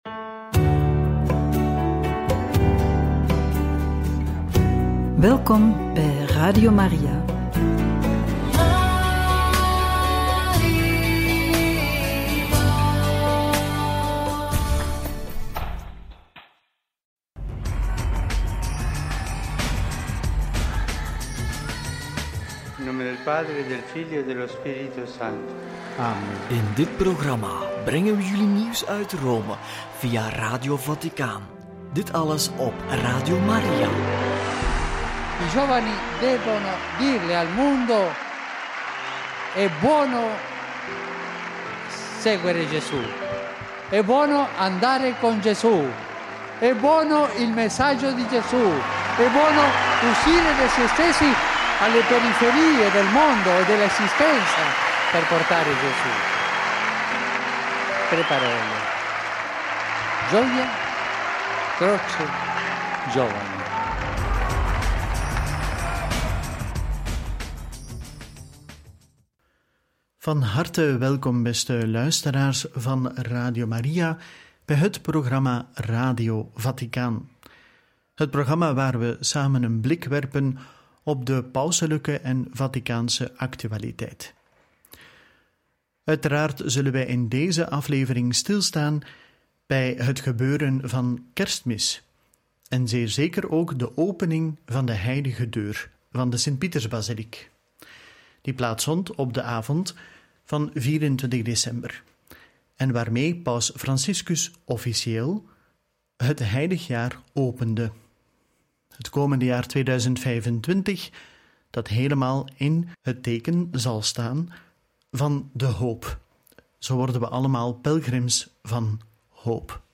Paus opent Heilige Deur Sint-Pietersbasiliek – Homilie van Kerstmis – Urbi et Orbi – Radio Maria